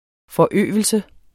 Udtale [ fʌˈøˀvəlsə ]